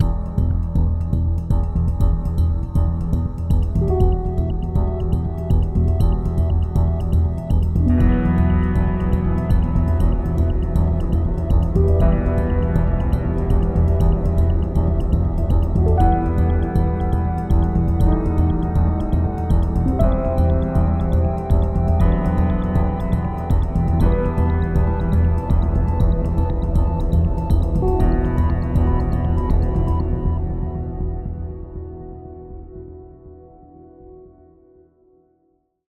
Added Ambient music pack. 2024-04-14 17:36:33 -04:00 9.9 MiB Raw Permalink History
Ambient Concern cut 30.wav